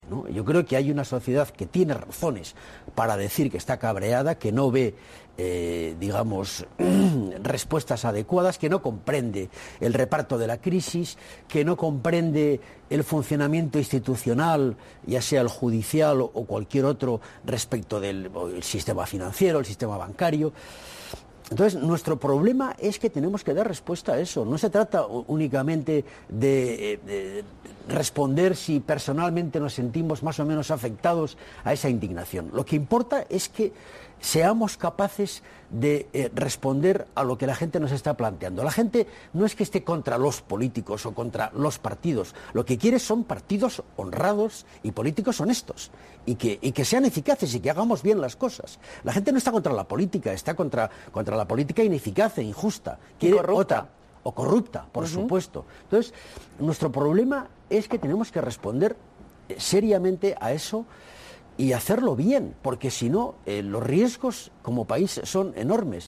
Ramón Jáuregui en los Desayunos de TVE 4-04-13